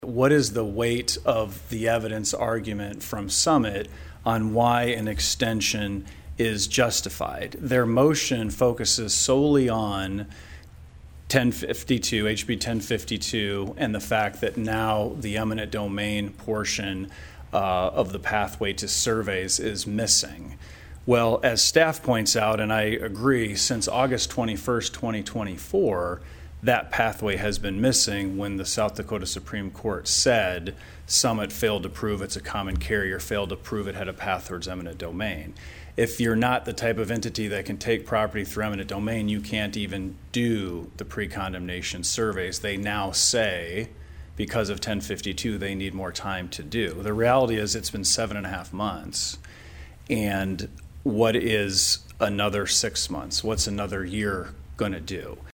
PIERRE, S.D.(HubCityRadio)- The South Dakota Public Utilities Commission held their meeting Thursday in Pierre.  One of the main topics was the status of the CO2 pipeline application made by Summit Carbon Solutions.